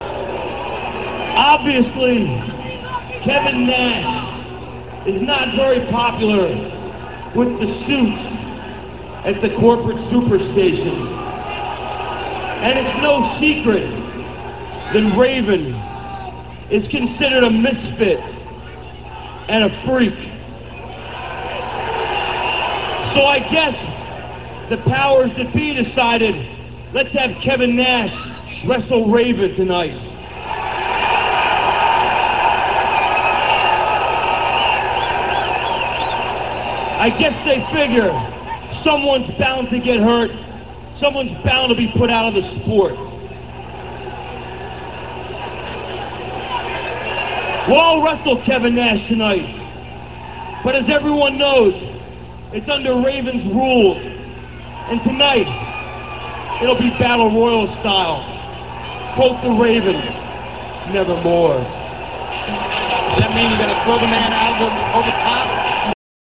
- This speech comes from Thunder - [2.26.98]. Raven says WCW considers him a misfit, thus he is being forced to fight Kevin Nash, so that they'll take each other out of wrestling.